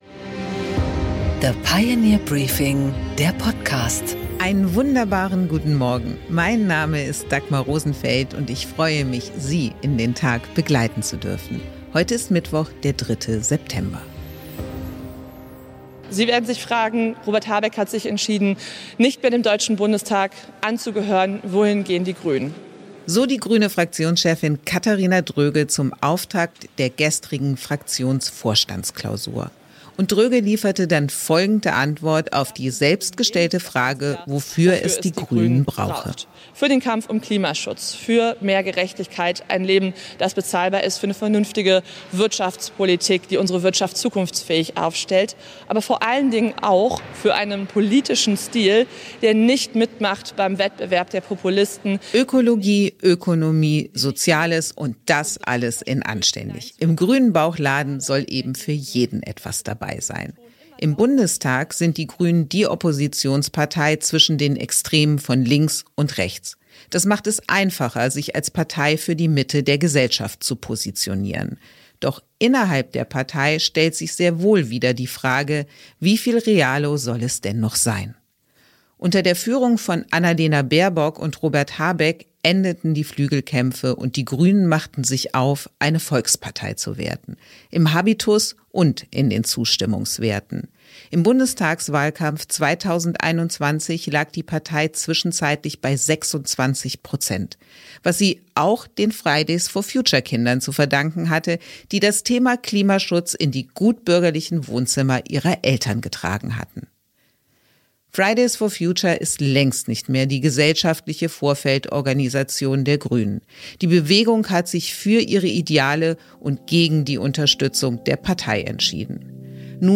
Dagmar Rosenfeld präsentiert das Pioneer Briefing
Im Gespräch: Tim Klüssendorf, Generalsekretär der SPD, äußert sich im Gespräch mit Dagmar Rosenfeld zur Zukunft der Rente und zu sozialpolitischen Reformen.